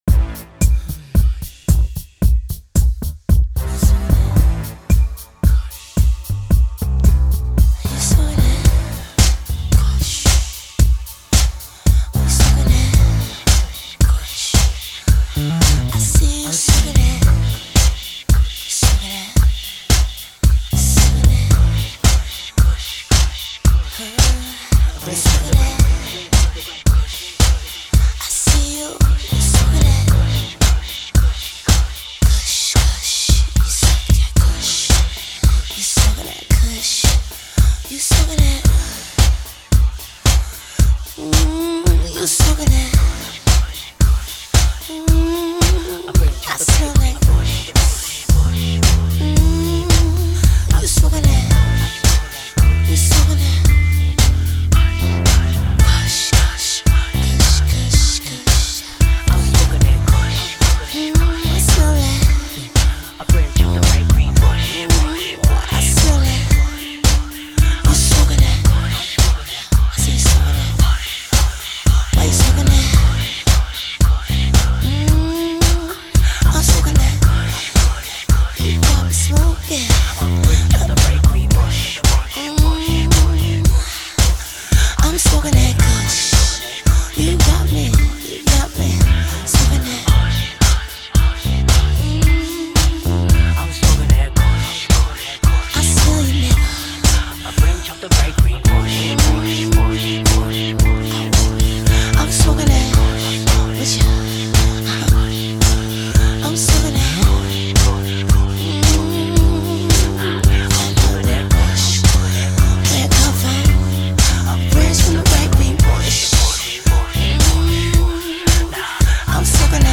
Hiphop
extra dope uptempo dance track